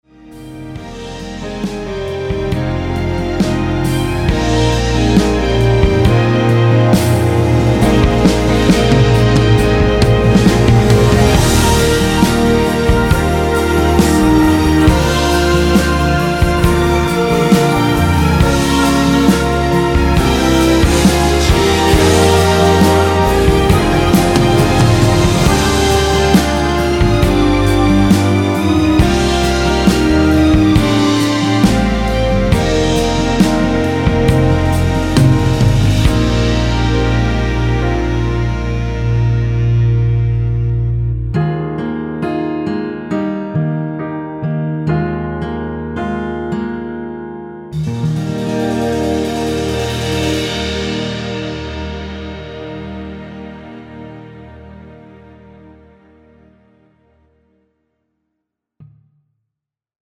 이곡은 코러스가 마지막 한부분만 나와서 그부분만 제작이 되었습니다.(미리듣기 확인)
원키에서(-1)내린 코러스 포함된 MR입니다.
Eb
앞부분30초, 뒷부분30초씩 편집해서 올려 드리고 있습니다.